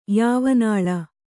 ♪ yāvanāḷa